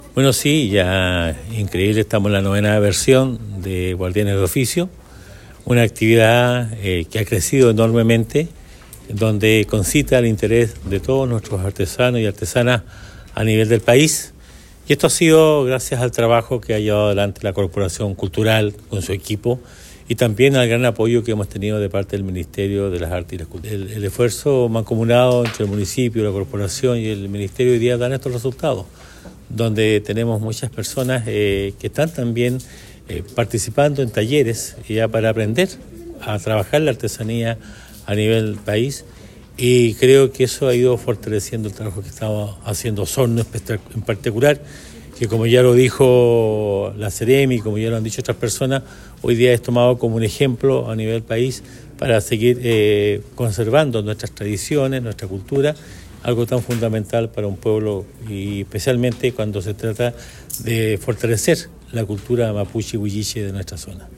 Este lunes, en la sala de sesiones del municipio de Osorno, se realizó el lanzamiento oficial de “Guardianes de Oficios”, una iniciativa que tendrá lugar los días 7, 8 y 9 de noviembre en el Centro Cultural de Osorno.
El alcalde de Osorno, Emeterio Carrillo, también expresó su apoyo al evento, valorando su impacto en la difusión y preservación cultural. Carrillo destacó que este tipo de actividades no solo enriquecen la vida cultural de Osorno, sino que también contribuyen a la identidad y cohesión social de la comunidad.